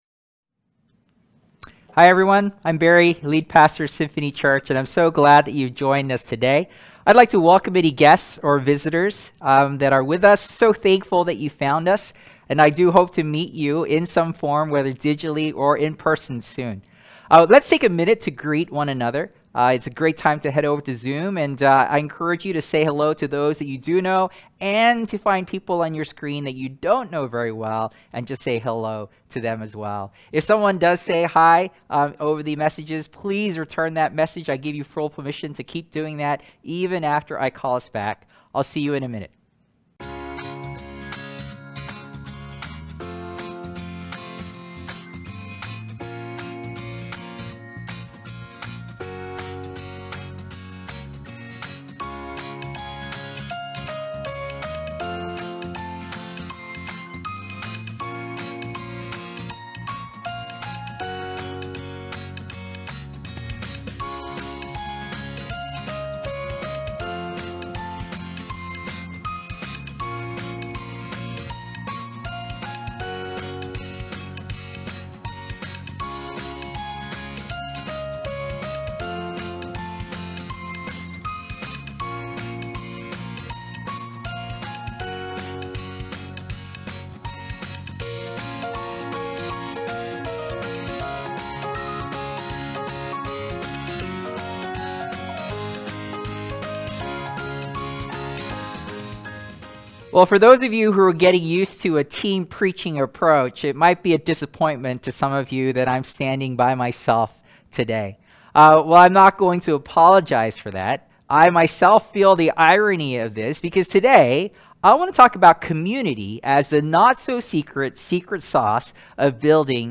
Sermons | Symphony Church